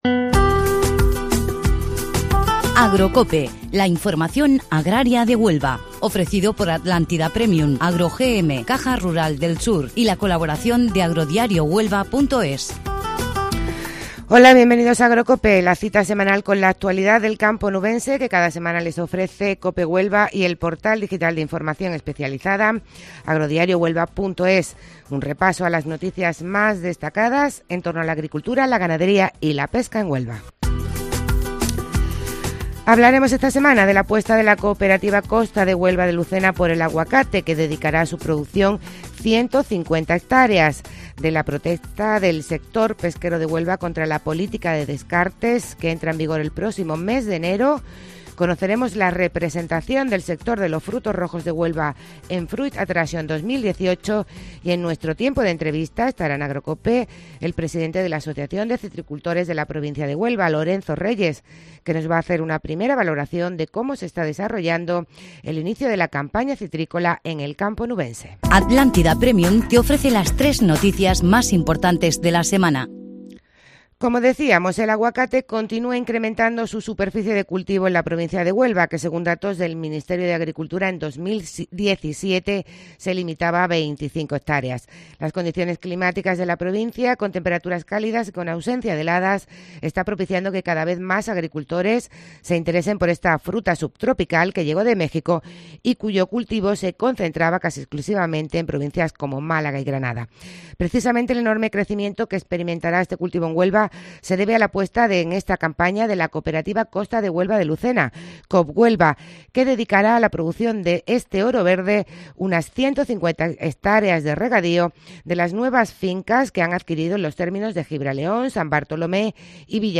Y entrevistamos